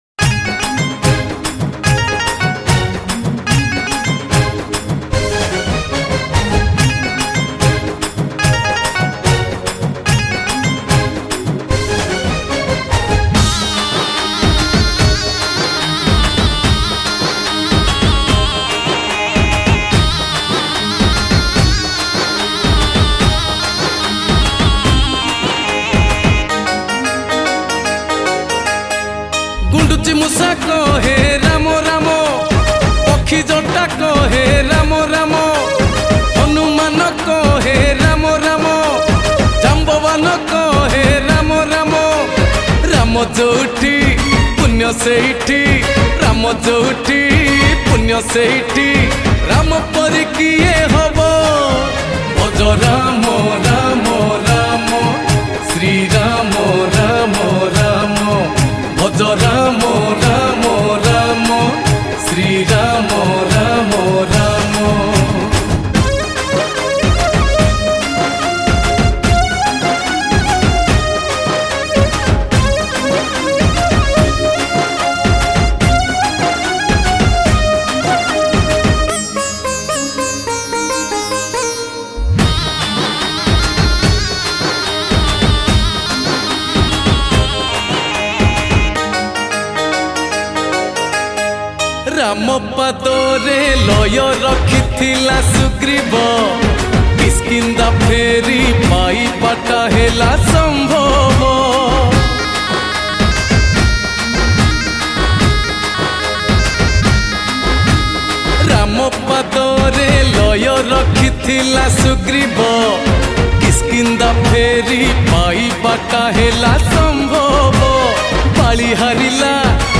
Pana Sankarati Special Bhajan